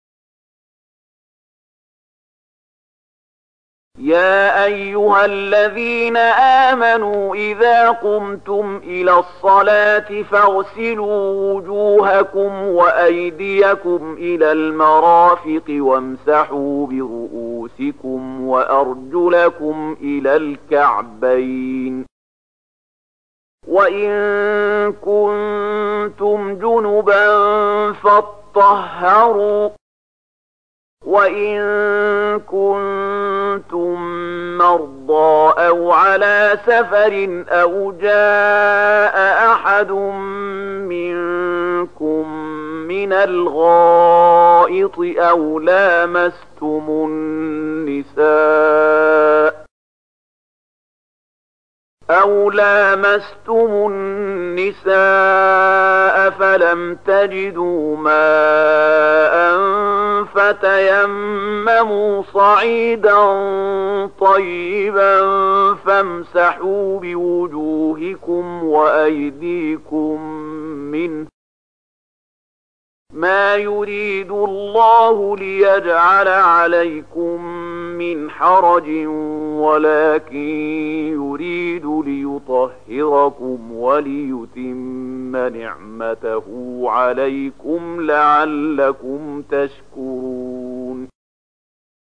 Bacaan murattal Syaikh Mahmud Khalilil Hushariy: